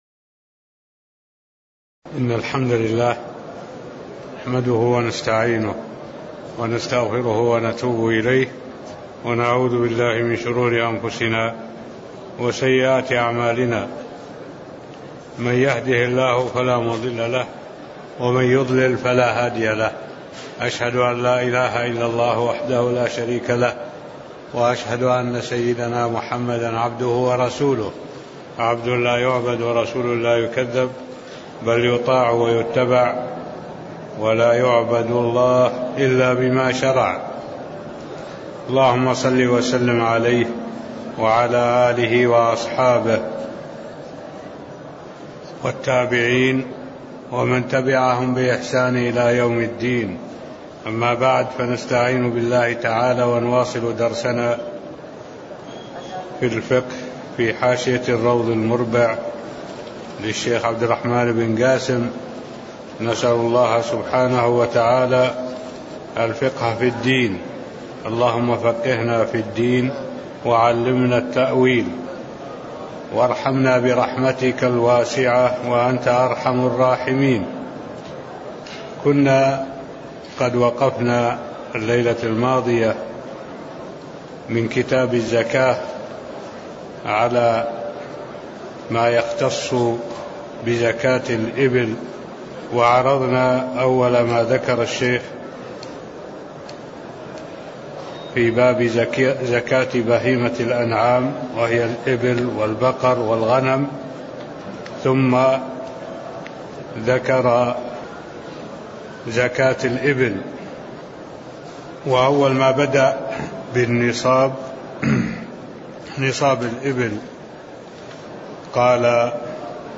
تاريخ النشر ٢٣ ربيع الأول ١٤٢٩ هـ المكان: المسجد النبوي الشيخ: معالي الشيخ الدكتور صالح بن عبد الله العبود معالي الشيخ الدكتور صالح بن عبد الله العبود زكاة الإبل (007)